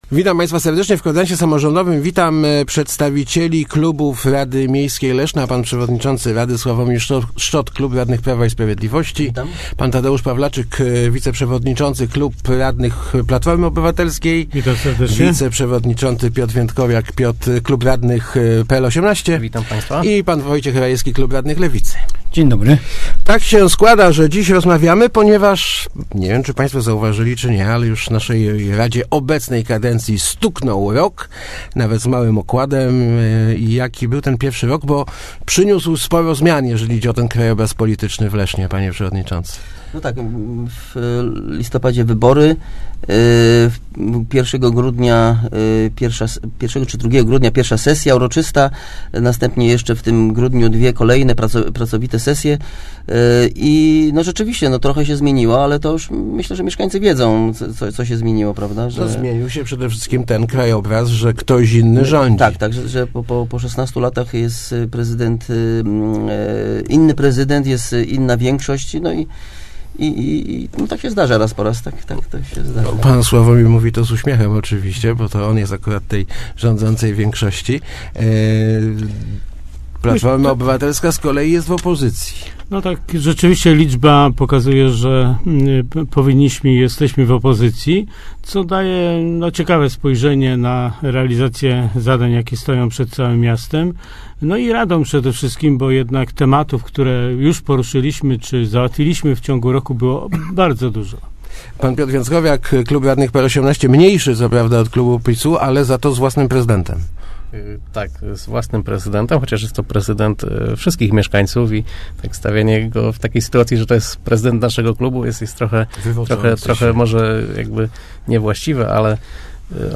W Radiowym Kwadransie Samorz�dowym radni podsumowali miniony okres.